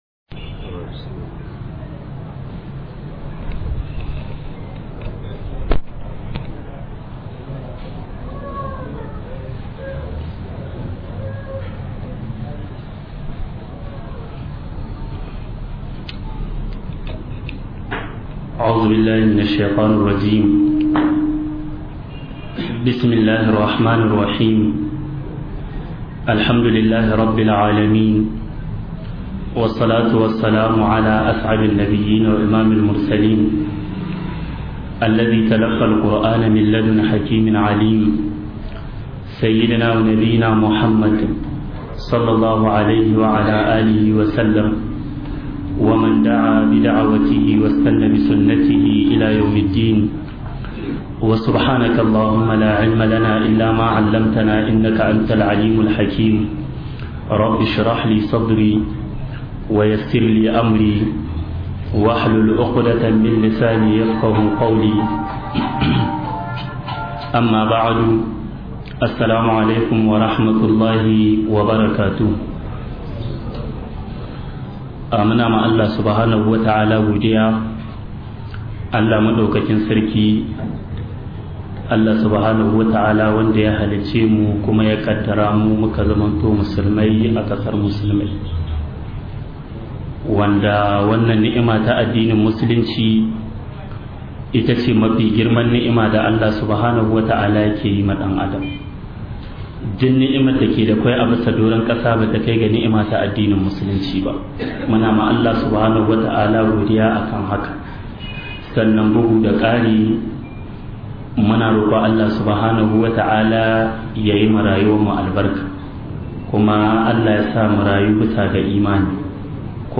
ADDINI-MUSLUMCI-YANA-DA-BUKATAR-JARUMAI - MUHADARA